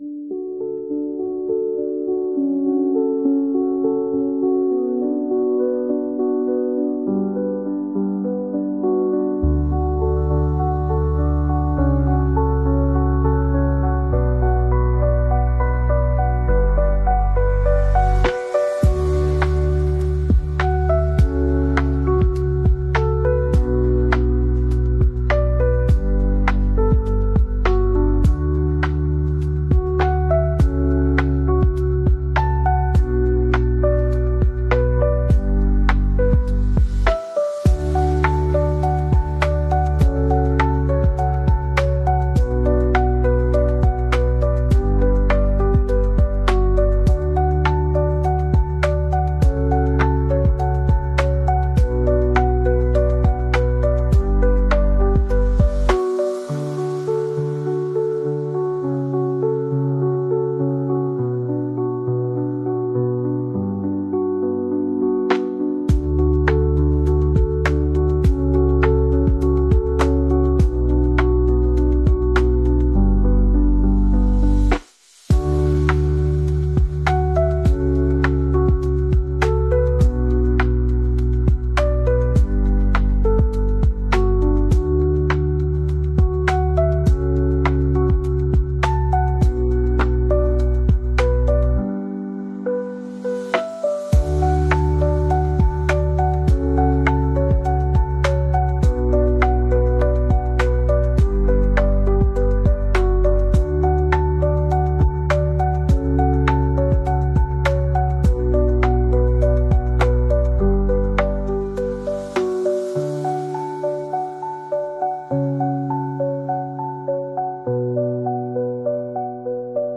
Una conversación íntima sobre legado, decisiones y el poder de la voz.